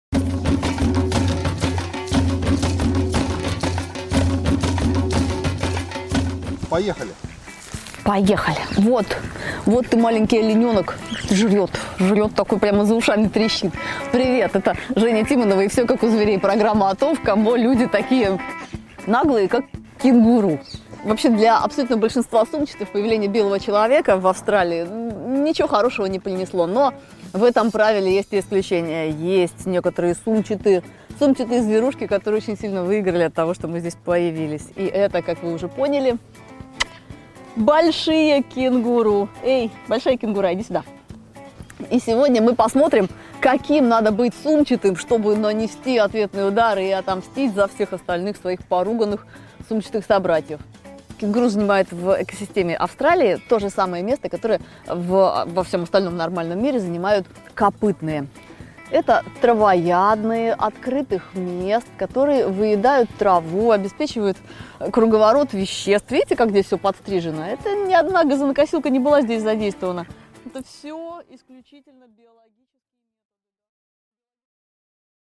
Аудиокнига Кенгуру: трекс, секс, спинифекс | Библиотека аудиокниг